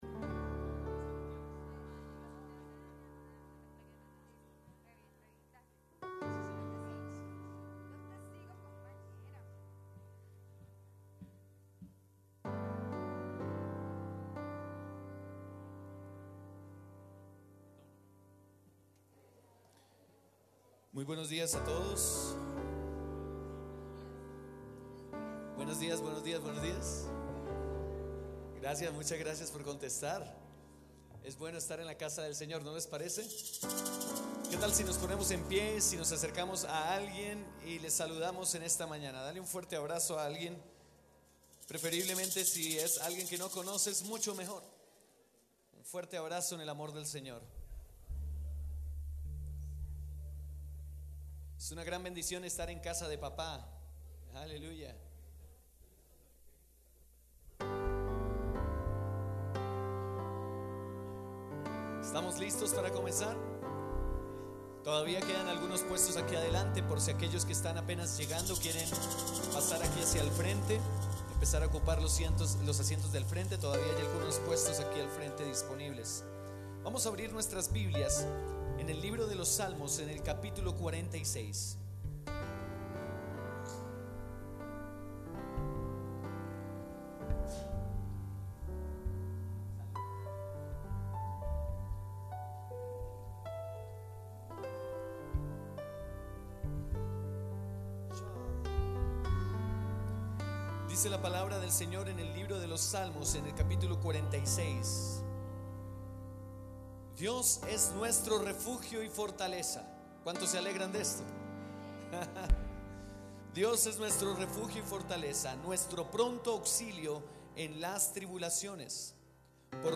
Lección 1: Alabanza marzo 15, 2015.